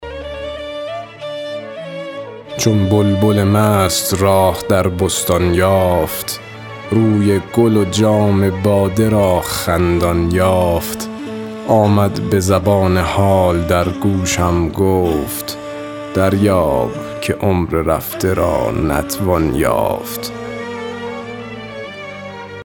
رباعی شمارهٔ ۲۵ به خوانش